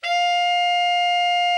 TENOR 34.wav